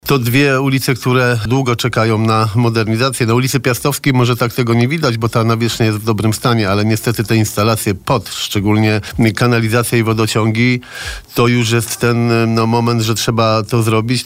– Piastowska będzie poszerzona o dwa ronda. Będzie wymieniona kanalizacja i sieć wodociągowa. Będzie – długo oczekiwana – droga rowerowa, w sąsiedztwie tej ulicy, ciągnąca się od Hulanki aż do dworca [PKP – red.] – mówił na naszej antenie Jarosław Klimaszewski, prezydent Bielska-Białej.